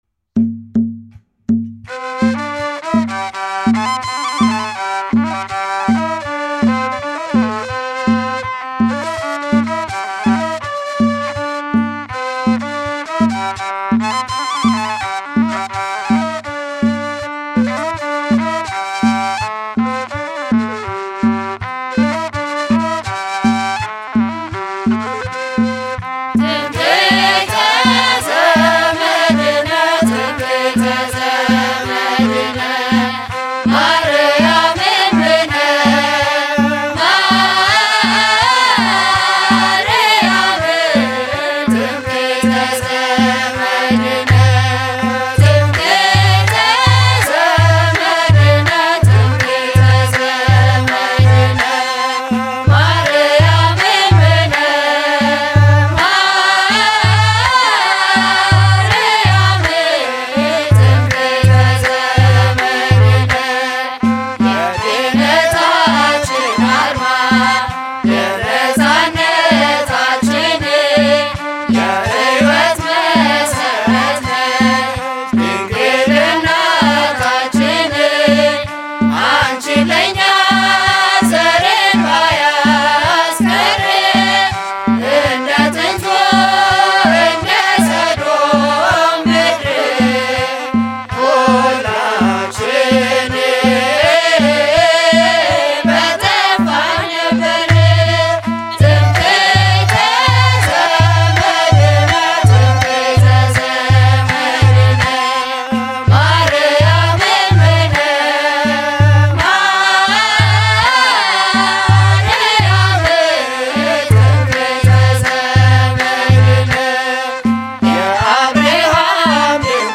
መዝሙር (ትምክሕተ ዘመድነ) December 23, 2018